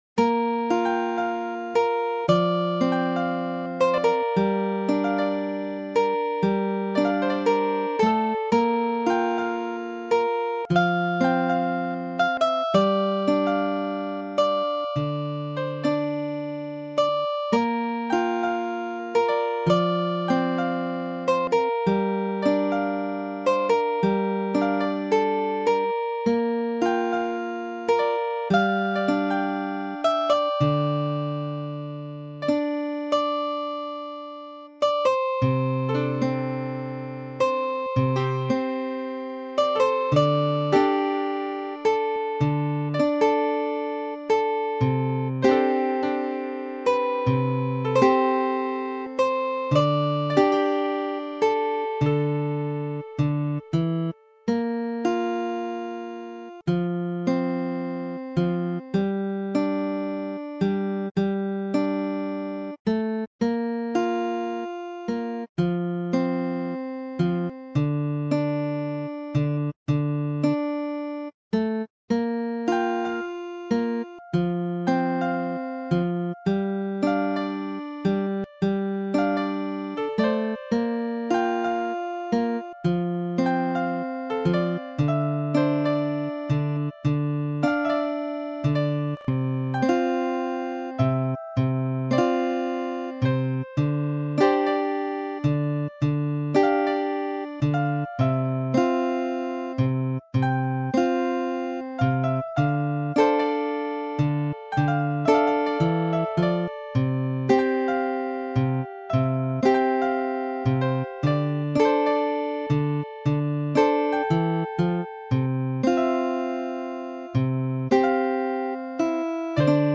Original Tune Guitar with Piano (probably suit an rpg adventure type game)